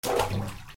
水に落とす
『ガボン』